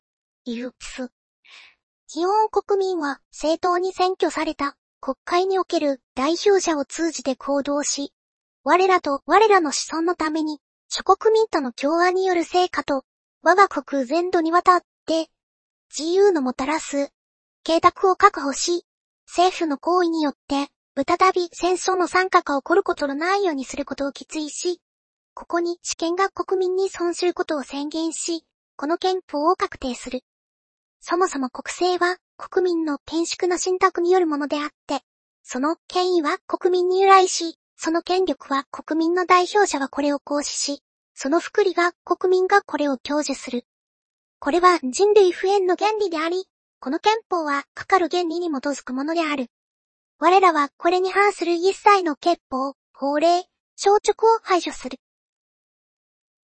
training_03_normal.mp3：「おまけ：WAV（+12dB増幅＆高音域削減）」でトレーニングした結果をそのまま変換した例
また、おまけ」のほうは高音域が削除されているせいか全体的に落ち着いた声になりました。
最初に「つっ」とか発音している部分がありますが、これは入力音声の無音の部分（もしくは小さな雑音が入っている部分）も変換しているためです。
training_03_normal.mp3